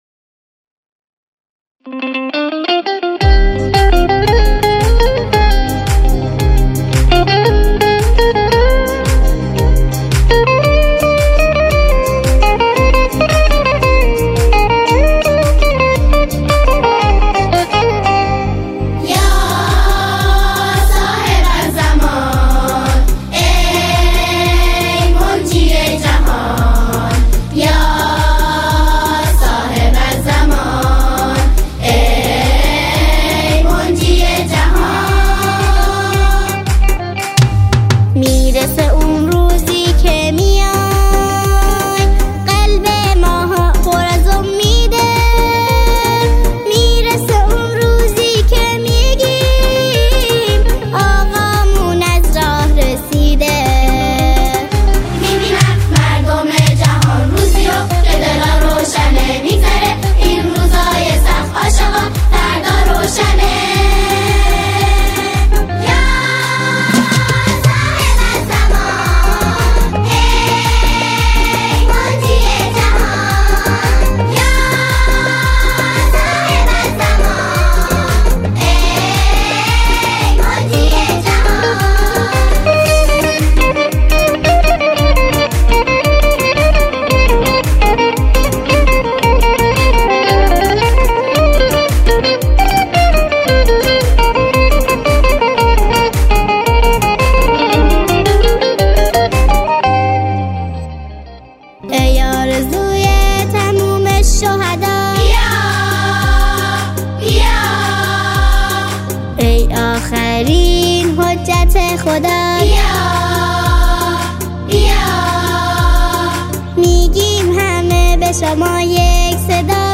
سرودهای امام زمان (عج)